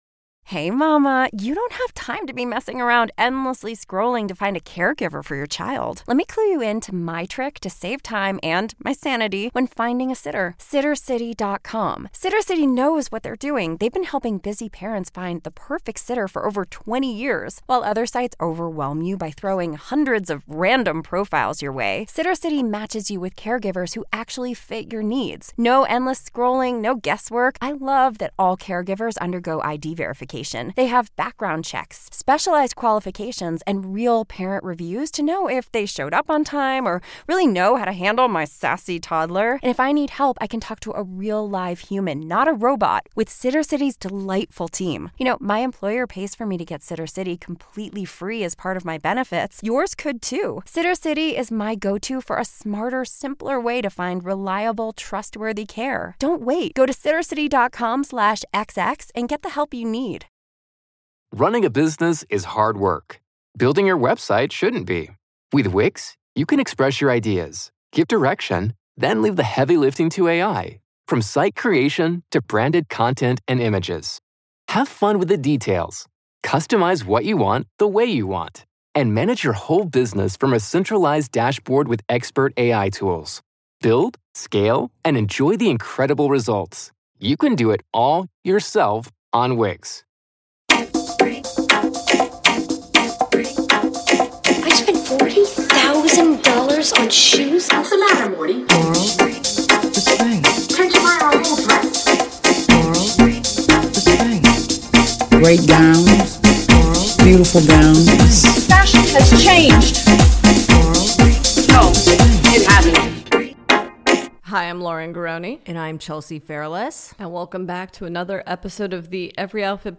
This week was a barren desert of fashion and pop culture news, so we decided to answer some hotline calls instead.